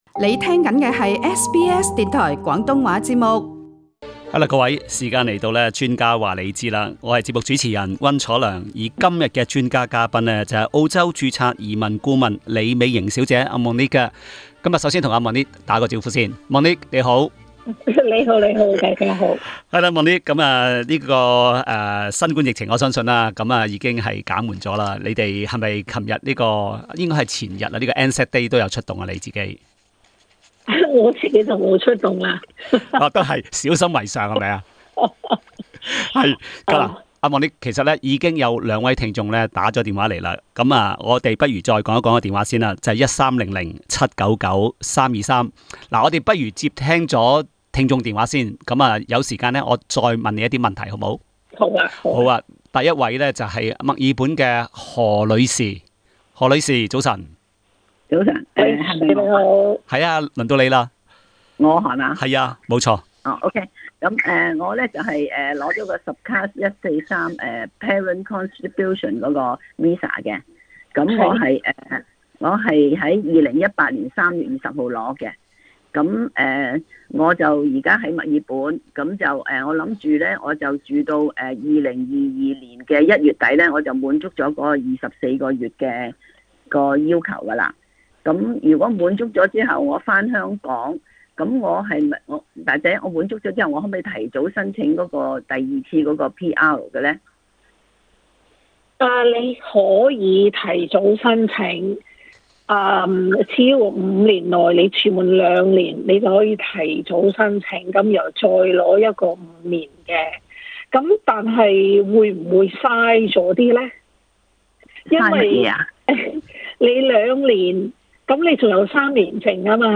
解答聽眾問題